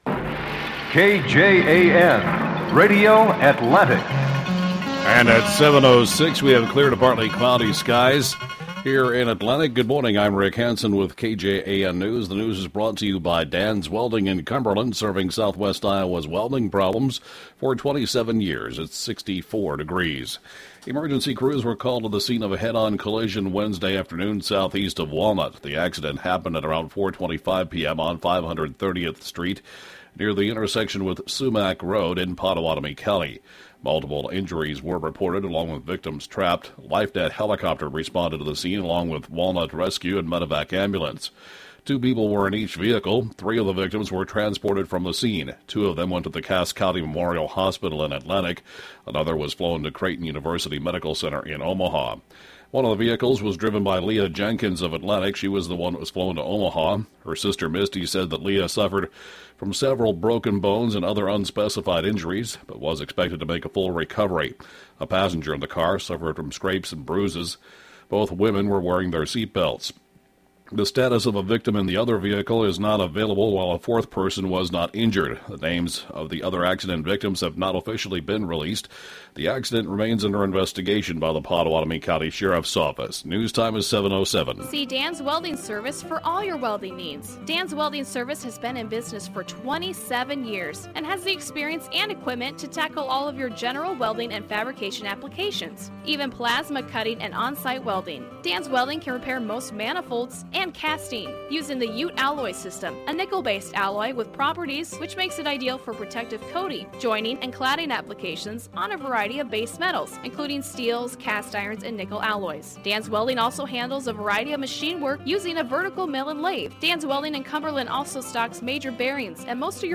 8AM Newscast 8-15-12